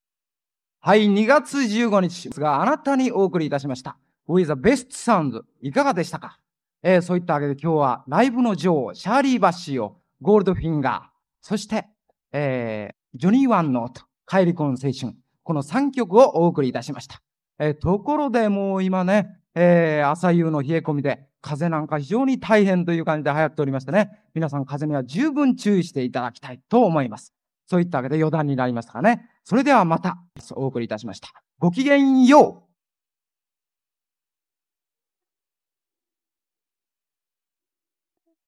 1976年2月、高田馬場BIGBOXで開催されたDJ大会。
▶ DJ音声⑦（エンディング）
⑦DJの声-最後のコメント-7.mp3